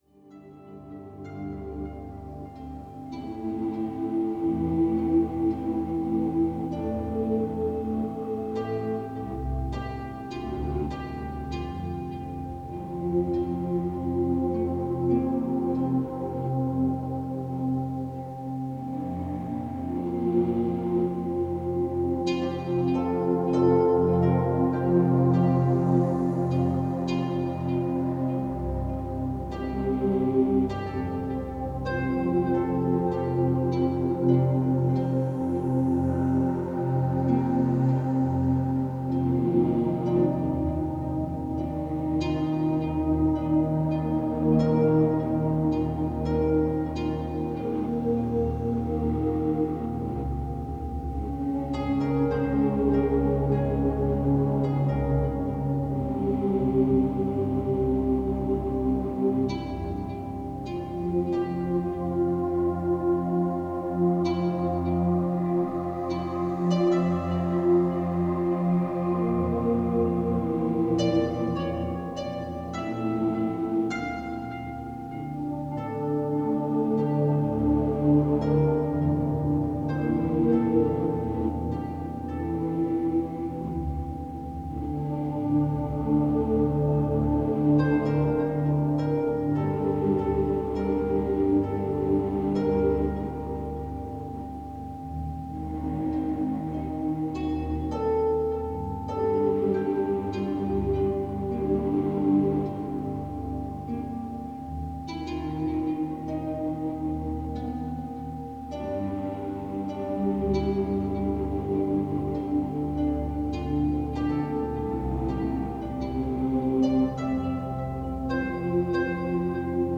Колыбельные мелодии для взрослых (помогают уснуть)